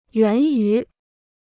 源于 (yuán yú) Kostenloses Chinesisch-Wörterbuch